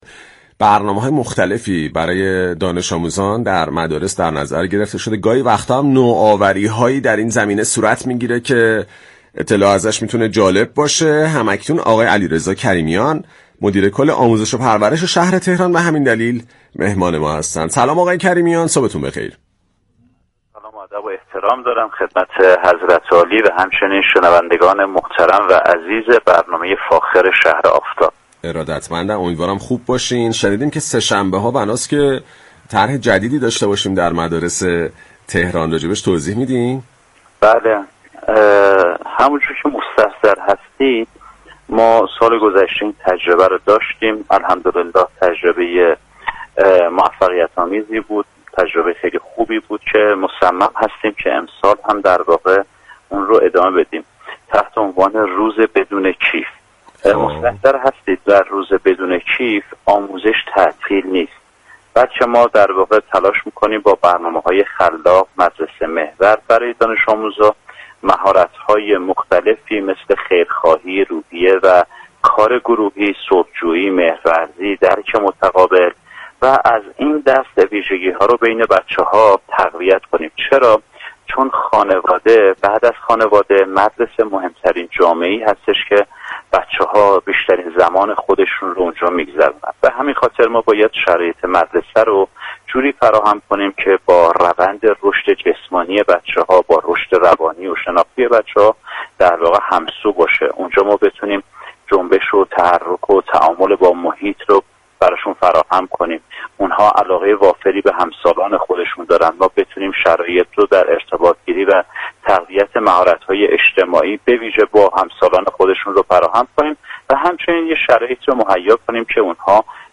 به گزارش پایگاه اطلاع رسانی رادیو تهران، علیرضا كریمیان مدیركل آموزش و پرورش شهر تهران در گفت و گو با «شهر آفتاب» درخصوص طرح «روز بدون كیف» اظهار داشت: در «روز بدون كیف» آموزش تعطیل نیست.